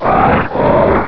Cri de Camérupt dans Pokémon Rubis et Saphir.